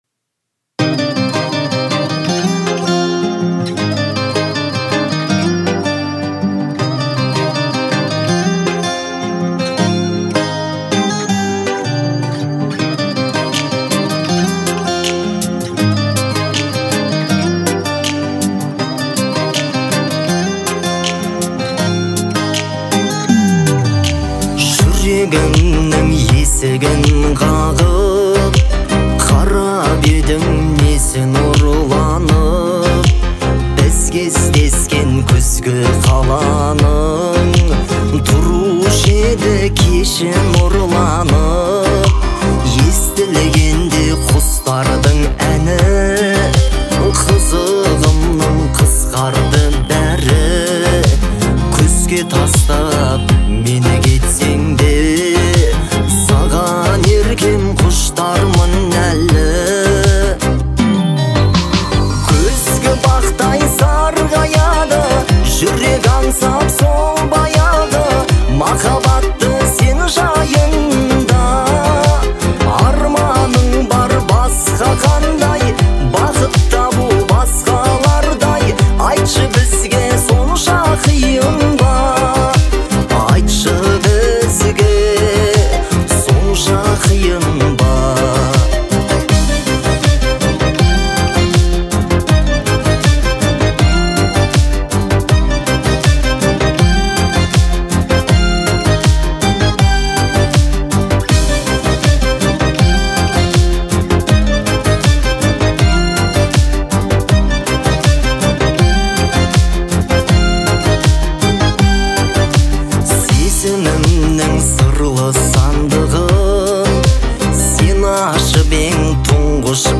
это трогательный музыкальный произведение в жанре поп-фолк